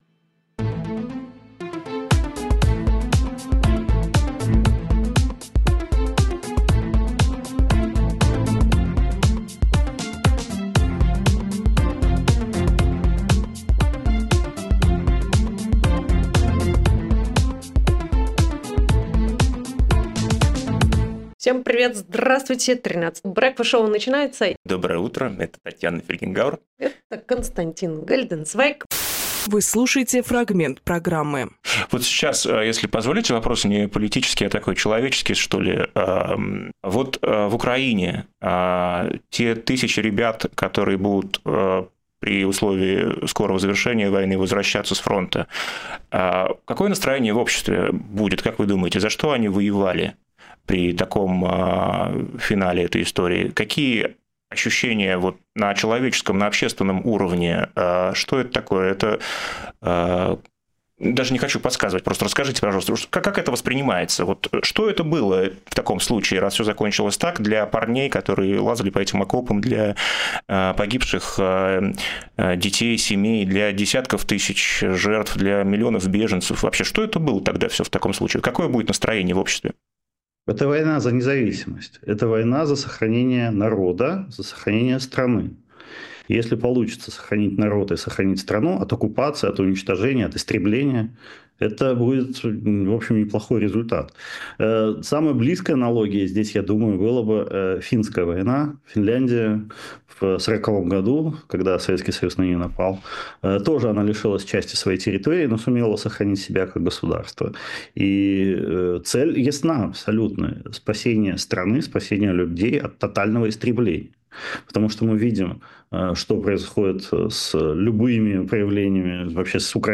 Фрагмент эфира от 13.02.25